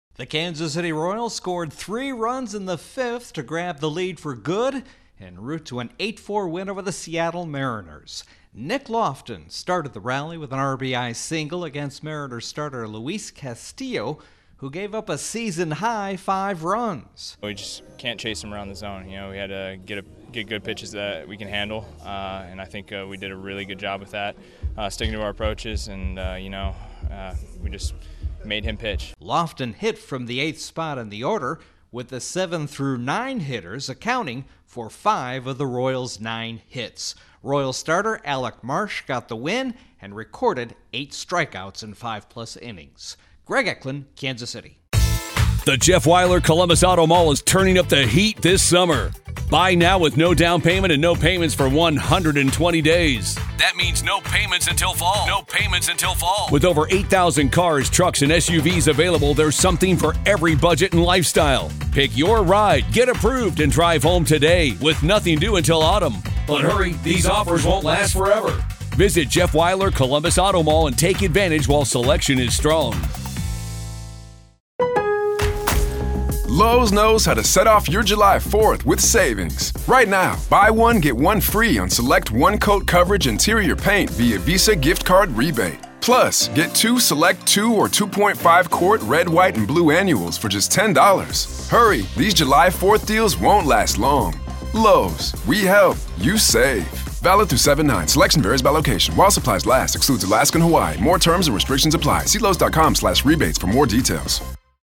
The Royals double up the Mariners. Correspondent